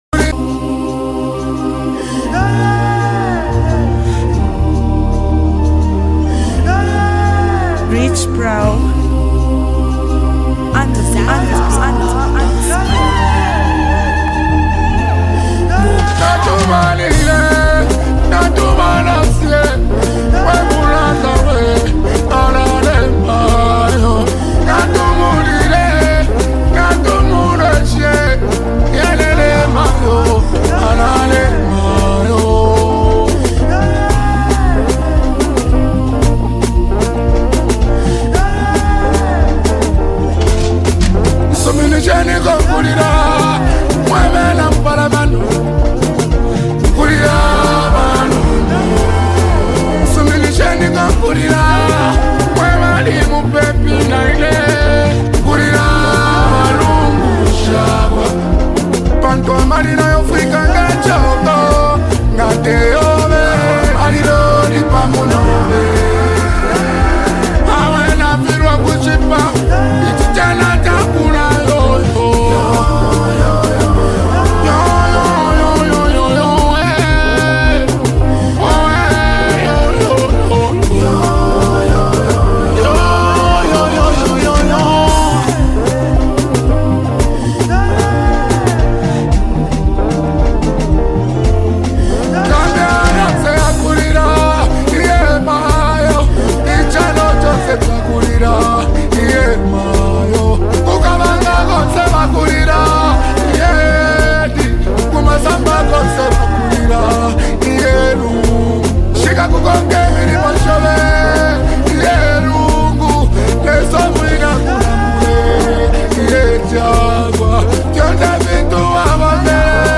Known for blending Afro-pop with deep cultural tones
a soulful and sorrowful anthem